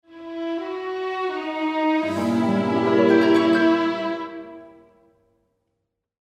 Sestřih melodií nahraných Janáčkovou filharmonií Ostrava pro použití např. jako tel. vyzvánění, upozornění na sms, budík najdete ke stažení níže (pro stažení klikněte pravým tlačítkem myši a kliknutím na „uložit odkaz jako“).